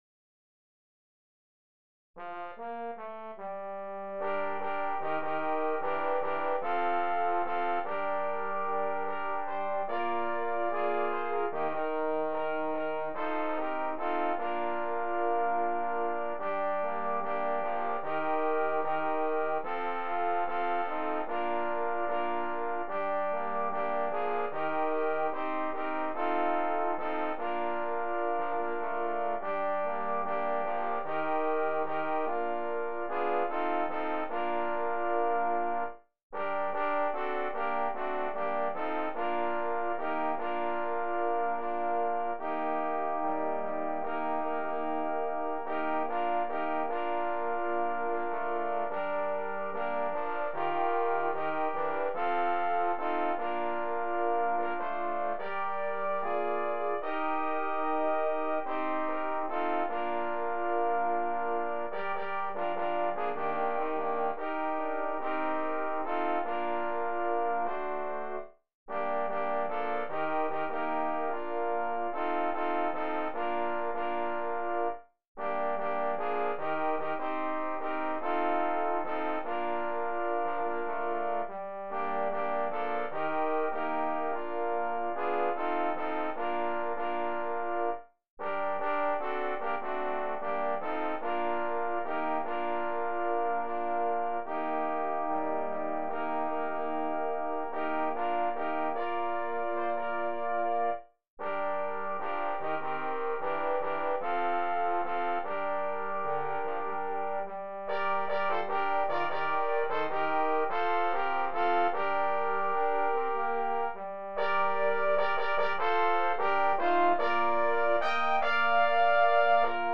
Digital audio track (no vocals).